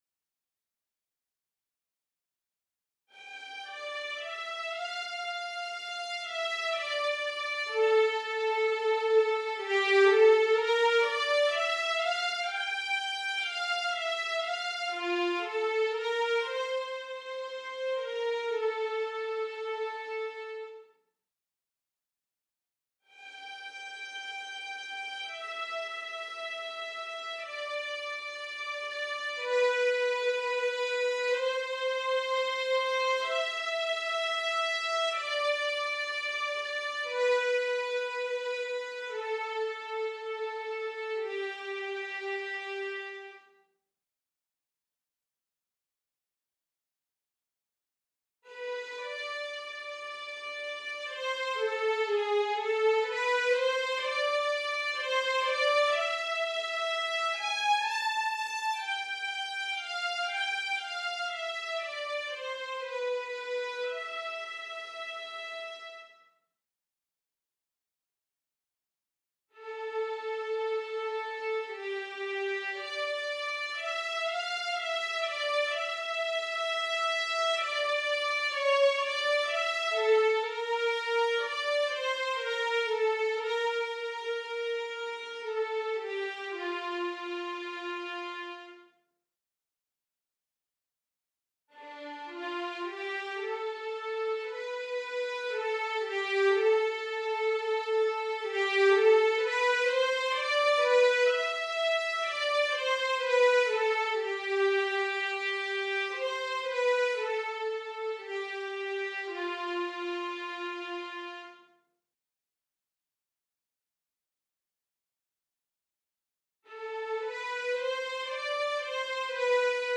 1. SOPRANO (Violins section/Auto)
gallon-v4s5-10-Violins_0.mp3